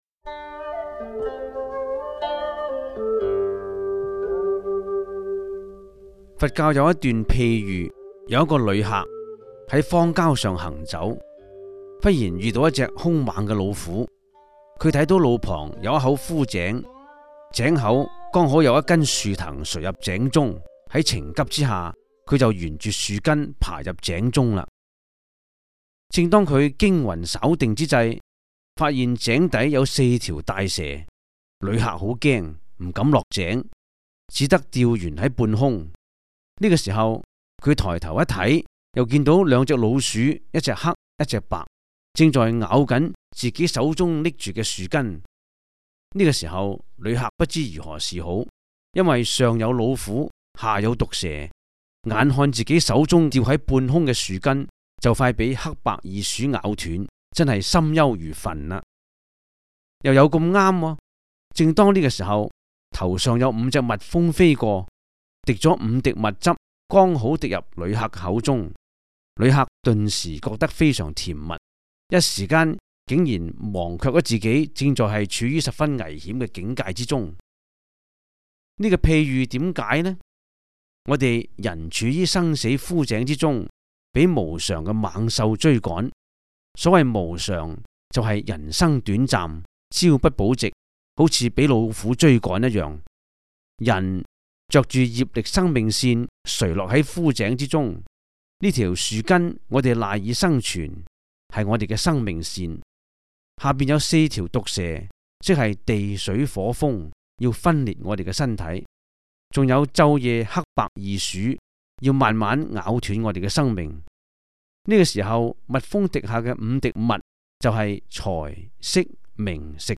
第 三 十 一 辑    (粤语主讲 MP3 格式)